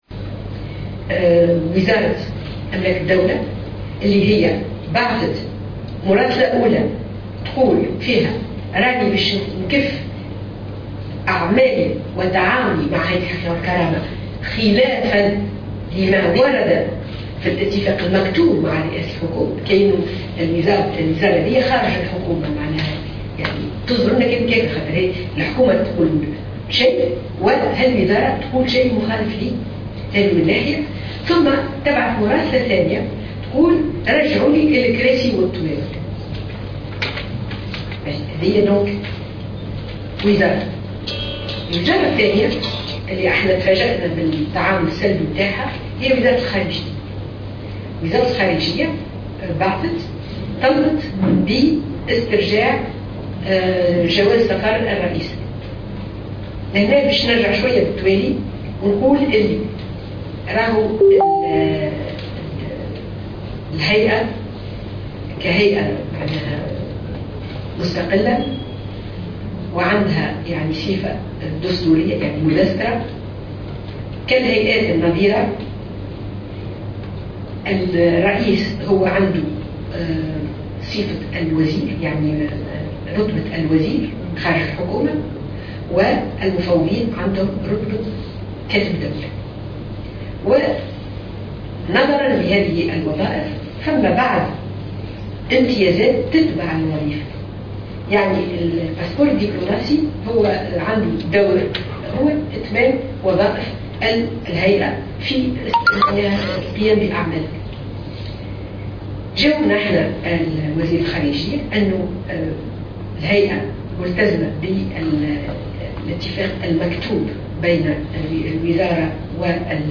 وقالت في ندوة صحفية "طارئة" عقدتها للغرض إنها تفاجأت صباح بـ"حجز" جواز سفرها الدبلوماسي رغم اعلامها المسبق لوزارة الخارجية بسفرها للمشاركة في ملتقى دولي بصفتها رئيسة الهيئة .